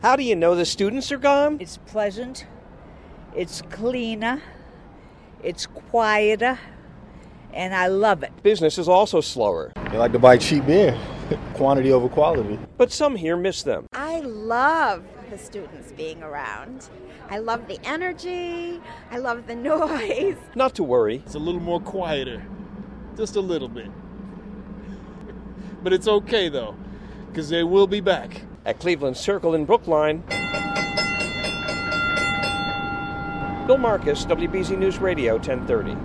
Courtesy of WBZ News Radio 1030.com